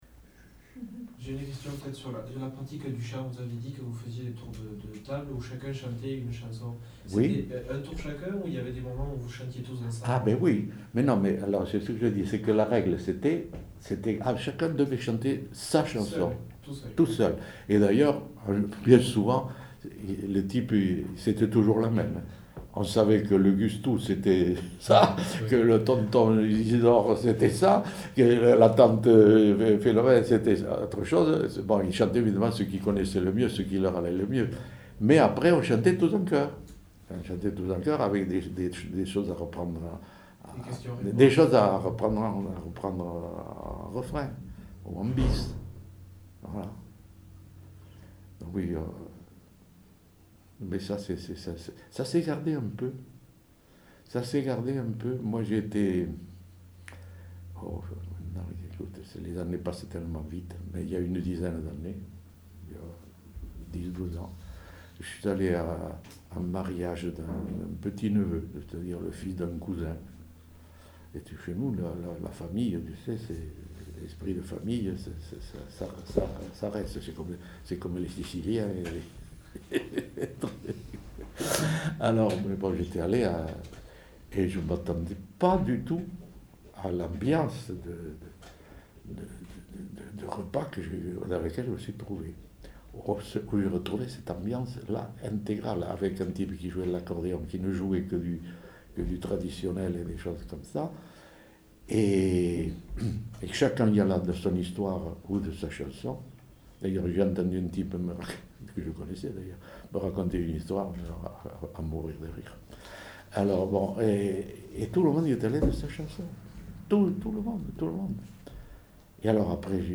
Lieu : Saint-Sauveur
Genre : témoignage thématique
Ecouter-voir : archives sonores en ligne